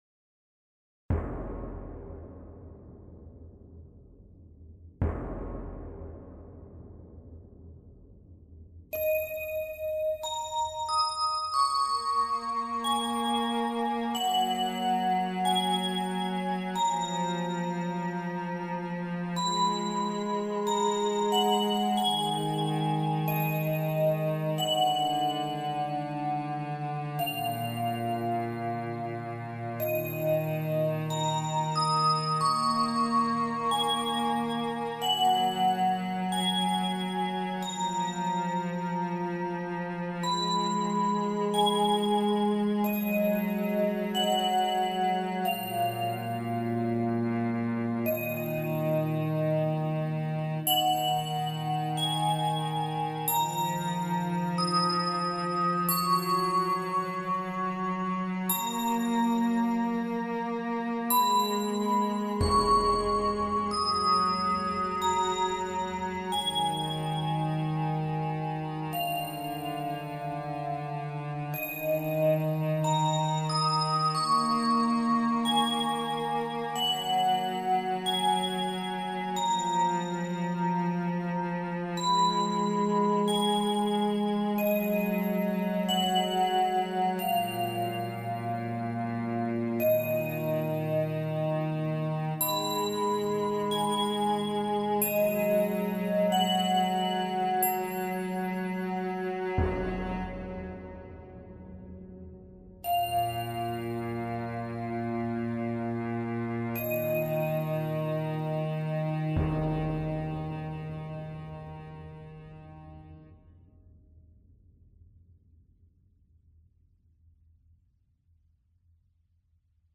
スローテンポロング暗い